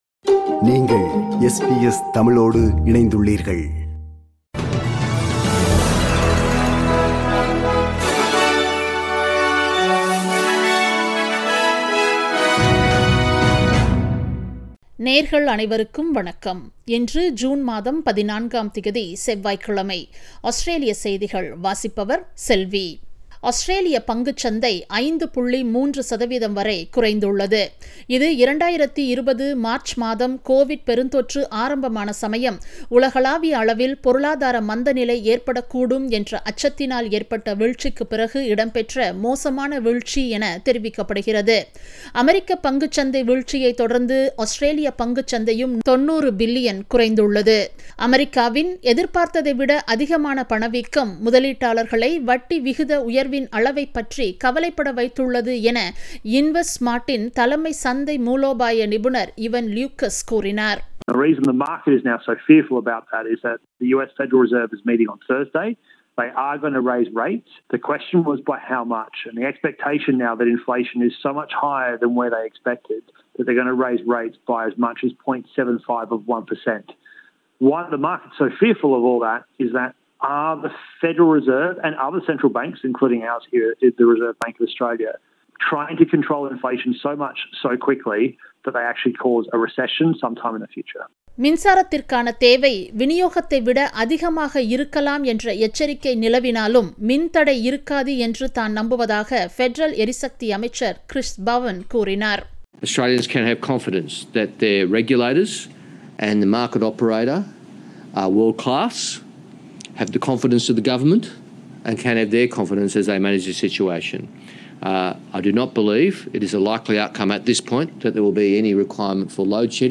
Australian news bulletin for Tuesday 14 June 2022.